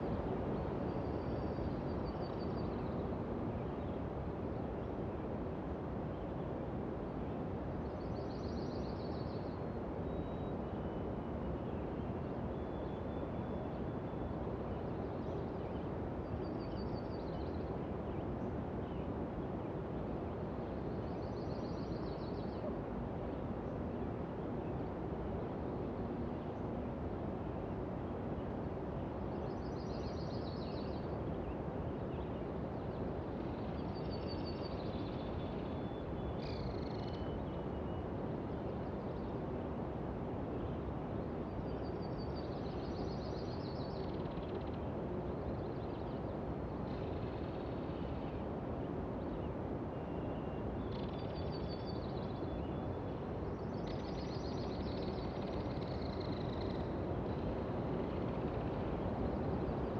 BGS Loops / Interior Day